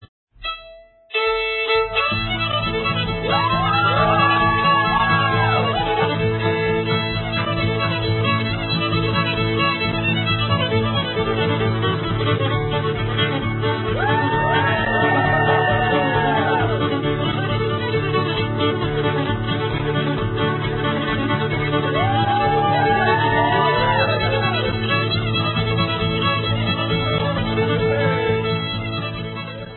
Listen to Alaskan Gwitchin Fiddler
for an example of the music played at our dances